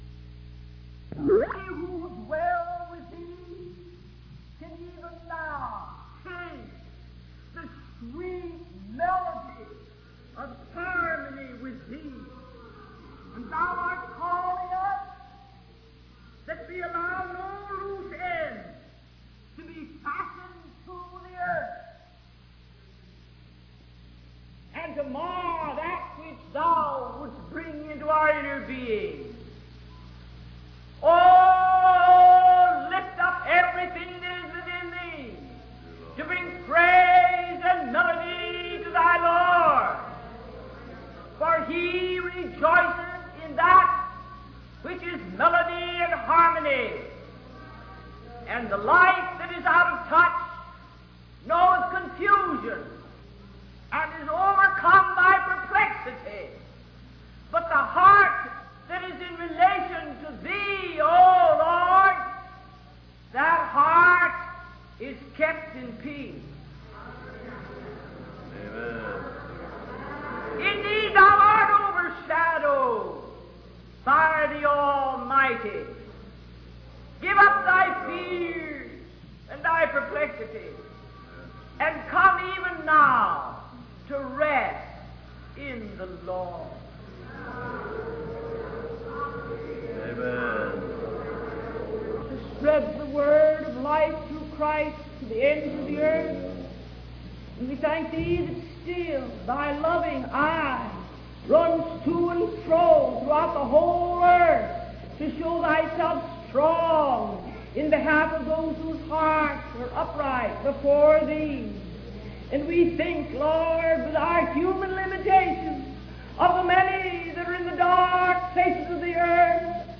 In this sermon, the preacher emphasizes the importance of offering a sweet sacrifice of worship and praise to the Lord, especially in times of suffering and hardship. The preacher encourages the congregation to surrender all and commit fully to God, even in the valley of sorrow. The sermon highlights the idea that true worship can only happen when we are caught up with who God is and forget ourselves.